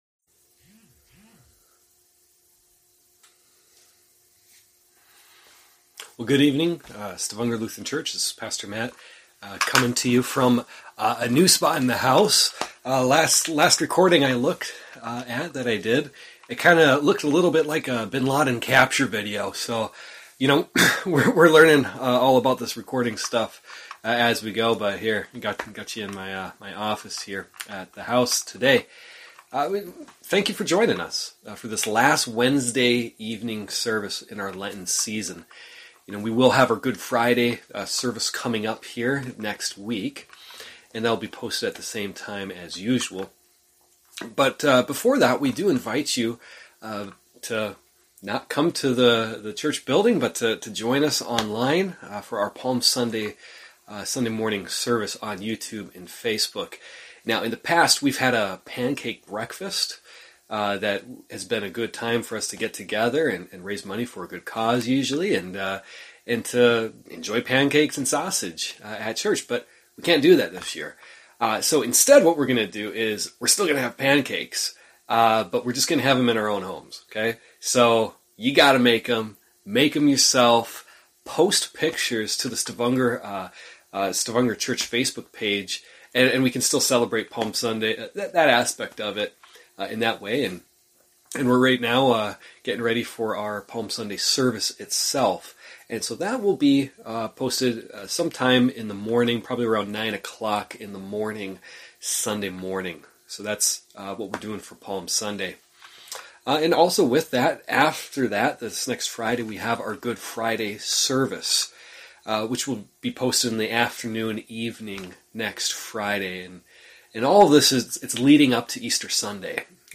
A message from the series "Lent."